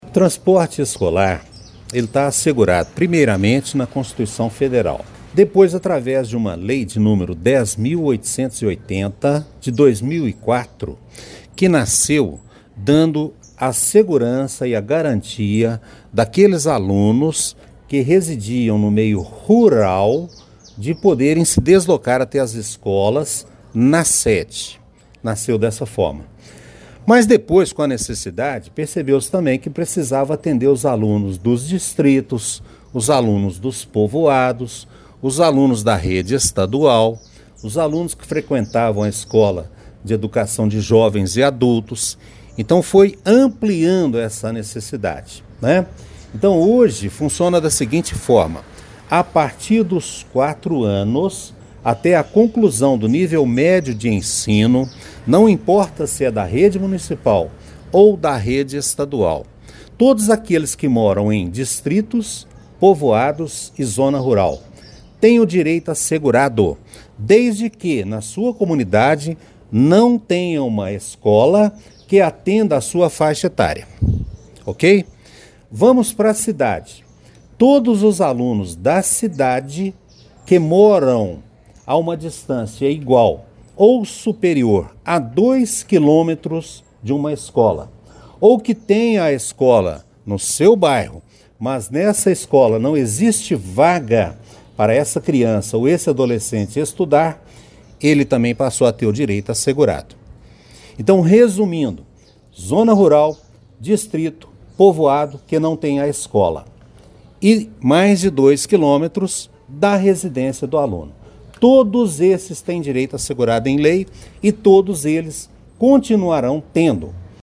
Durante coletiva de imprensa, o secretário de Educação, Marcos Aurélio dos Santos, destacou que o transporte escolar é um direito garantido pela Constituição Federal e por legislações específicas que regulam o setor.